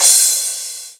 43_02_cymbal.wav